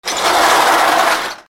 重い金属の扉 引き戸